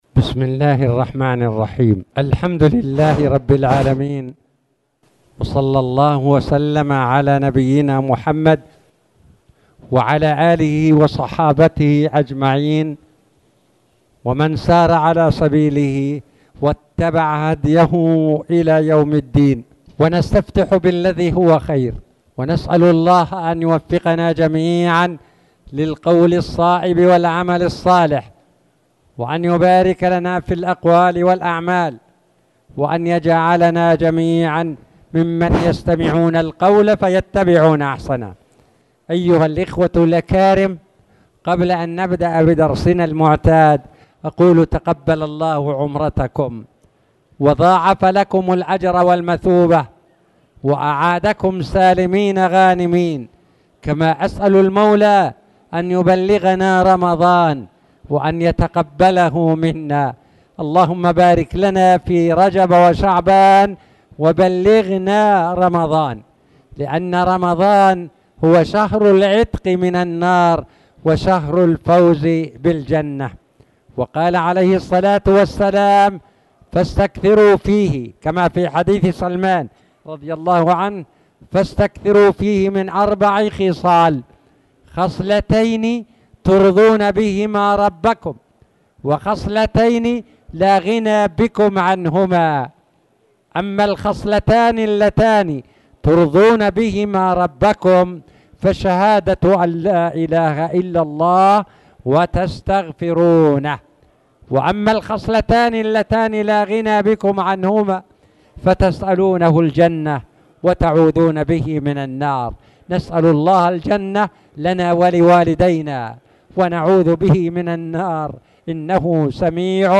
تاريخ النشر ٢٧ شعبان ١٤٣٨ هـ المكان: المسجد الحرام الشيخ